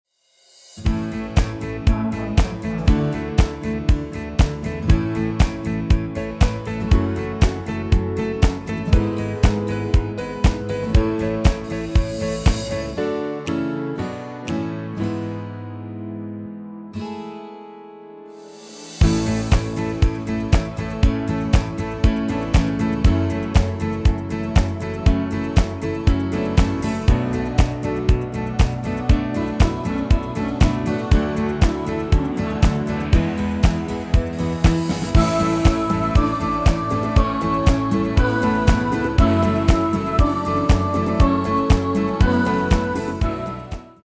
Extended MIDI File Euro 11.75
Demo's played are recordings from our digital arrangements.